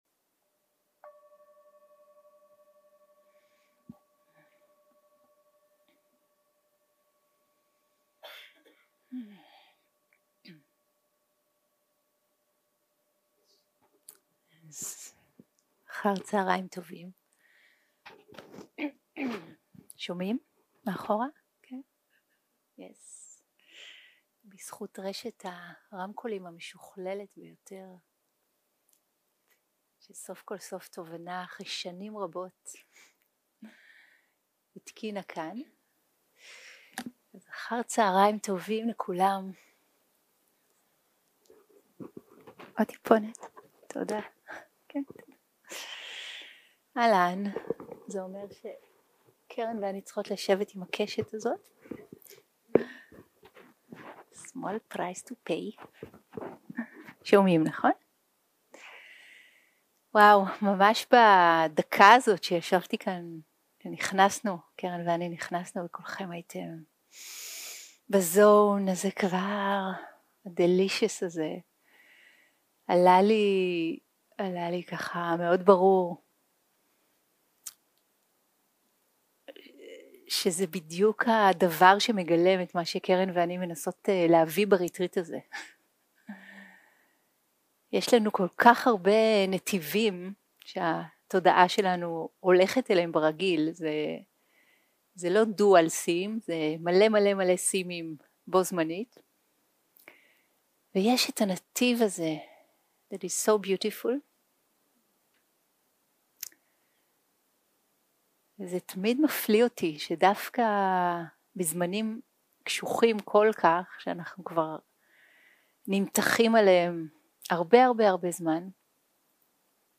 סוג ההקלטה: שיחת פתיחה